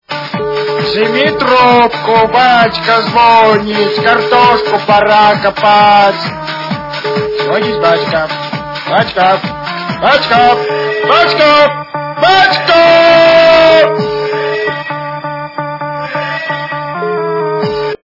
При прослушивании Звонок от отца - Батя звонит... качество понижено и присутствуют гудки.